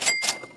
cash.mp3